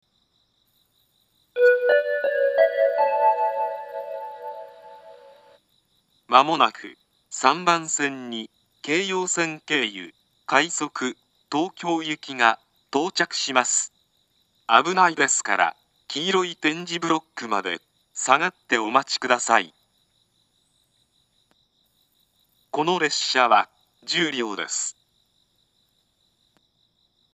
２０１７年１月１８日には放送装置が更新され、自動放送が合成音声に変更されました。
３番線接近放送
音程は低いです。